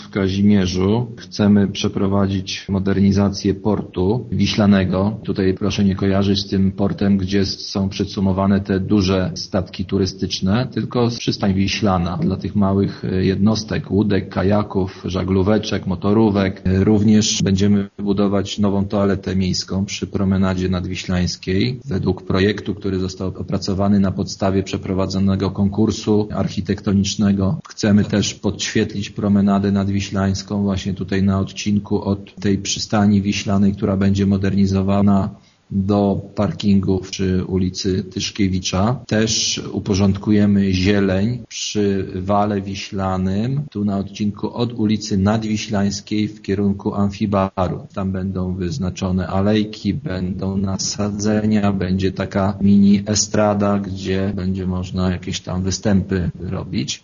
„Tylko w Kazimierzu Dolnym ich koszt szacuje się na 5 milionów 400 tysięcy złotych, z czego dofinansowanie z Unii Europejskiej wyniesie ponad 3 miliony 200 tysięcy czyli około 60 procent” - mówi burmistrz Grzegorz Dunia: